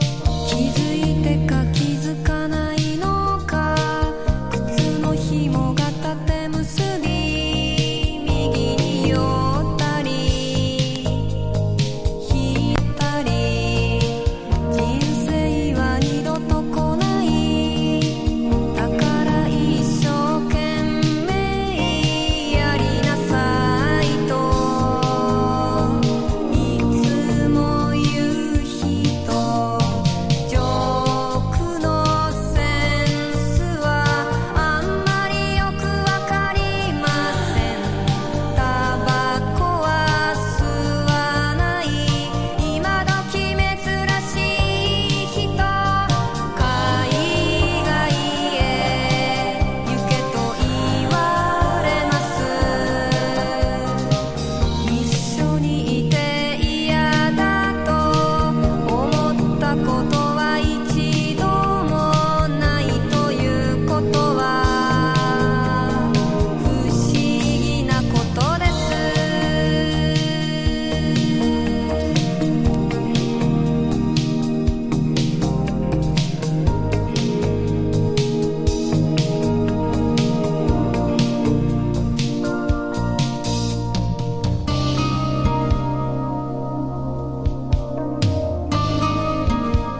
ジャパニーズ・オブスキュア・フォーク！？
全体的にリバーブの効いたサウンドもかなり独特です。
SSW / FOLK# CITY POP / AOR# 和モノ